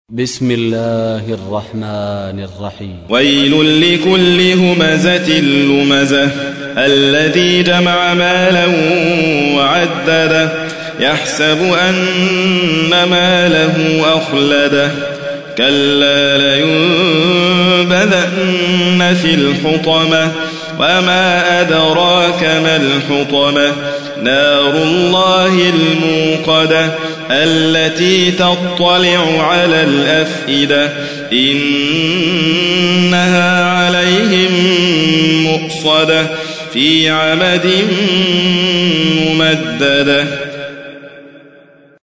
تحميل : 104. سورة الهمزة / القارئ حاتم فريد الواعر / القرآن الكريم / موقع يا حسين